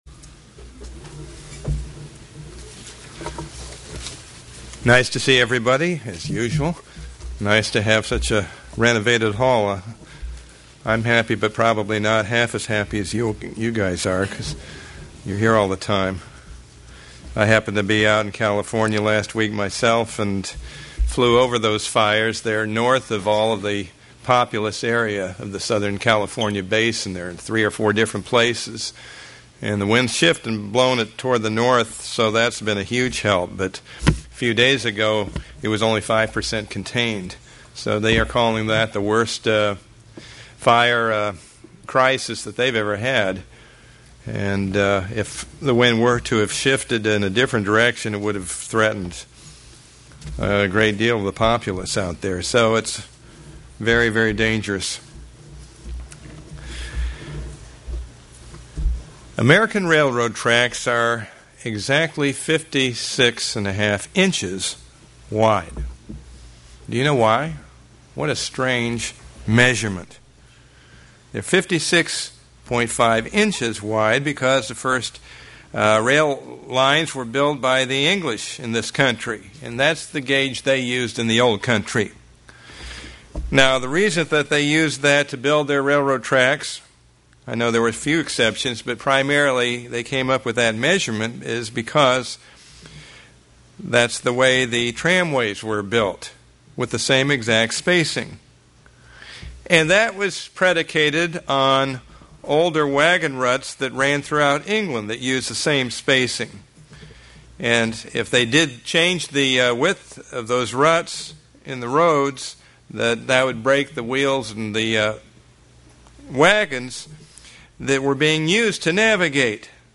We need to endure to receive the incredible Gifts when Christ returns UCG Sermon Studying the bible?